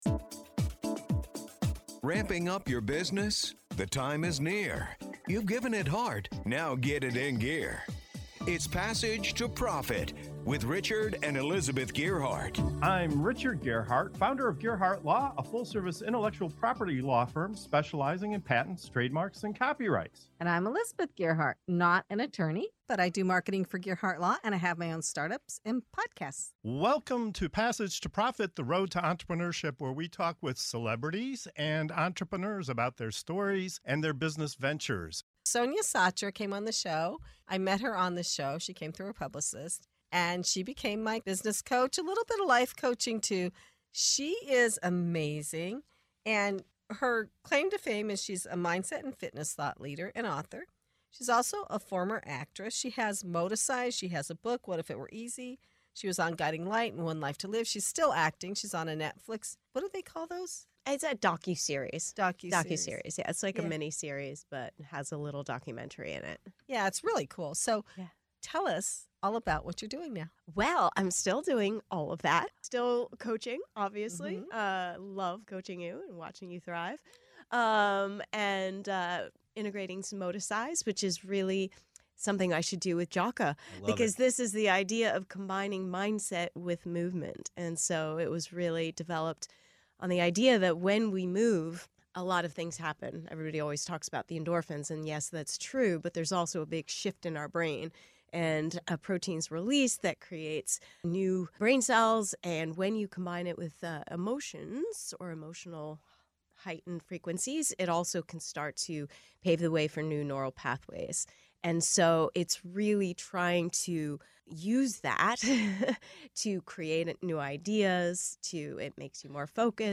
Tune in for an inspiring conversation that just might change the way you think, move, and thrive!